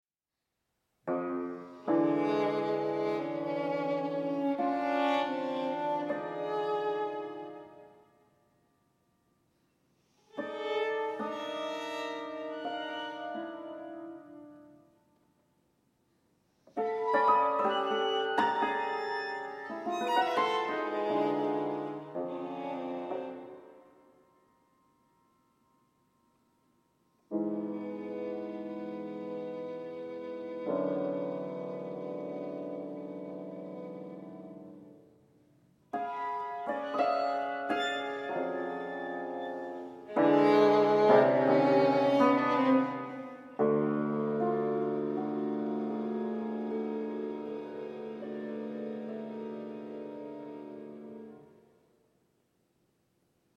for violin and cimbalom